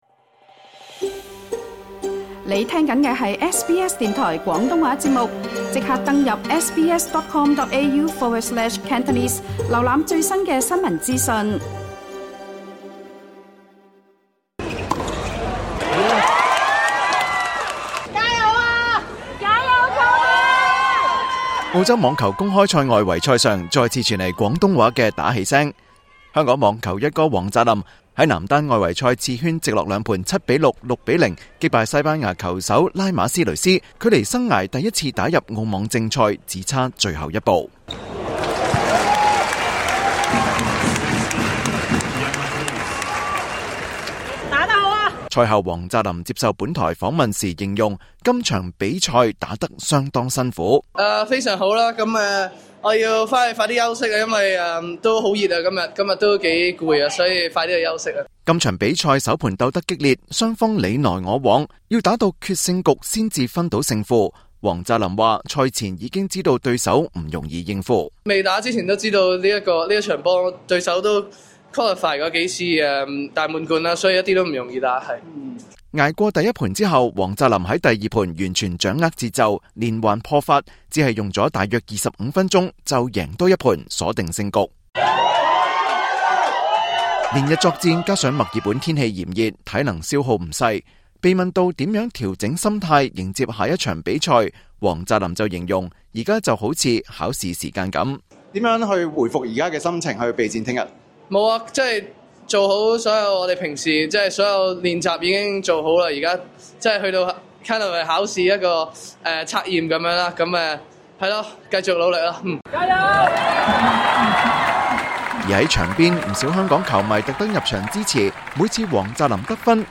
【澳網現場直擊】廣東話打氣聲響遍球場 黃澤林澳網外圍賽再闖一關
香港網球「一哥」黃澤林晉身澳洲網球公開賽外圍賽最後一關，距離正賽席位僅一步之遙。SBS廣東話在墨爾本澳網現場直擊今場比賽，整場賽事中，廣東話打氣聲此起彼落，見證他再闖一關。